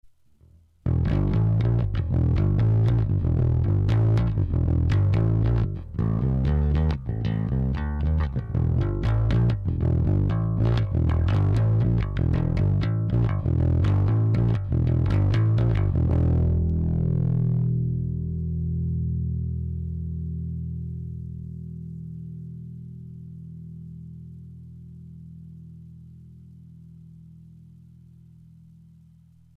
で、バランスをDRY側にすごく近づける。ただ、ちょっとOD成分も含ませてやる。これを間違えると上の音。
GEINは3時方向。これぐらいが音圧と音の硬さのバランスが取れてると思う。
で、イコライザはLOWを3時方向。HIGHを12時方向。
これでめっちゃ音が太くなります。